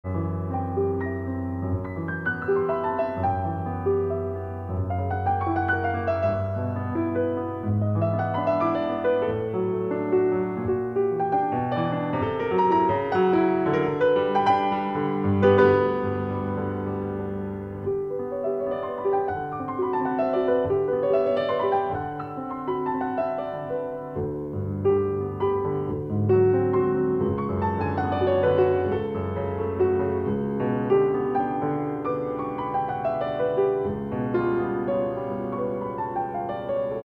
鋼琴